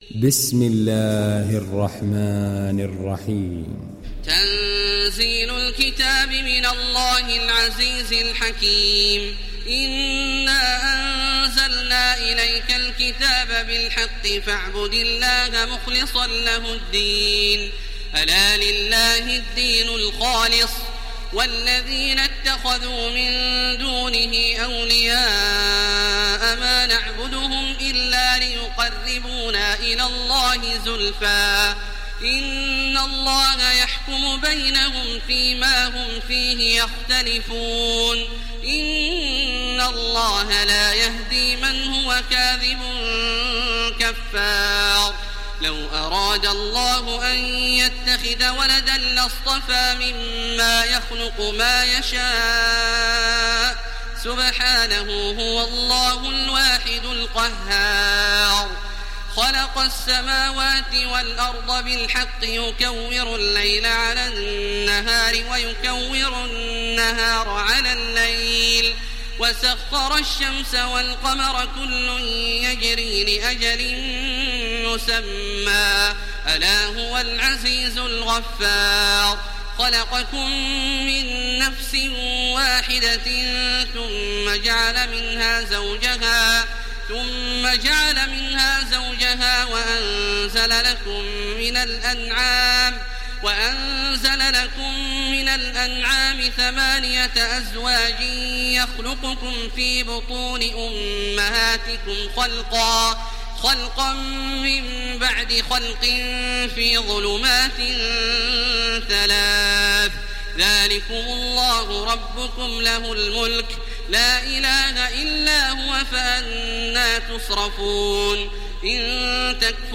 تحميل سورة الزمر mp3 تراويح الحرم المكي 1430 (رواية حفص)
تحميل سورة الزمر تراويح الحرم المكي 1430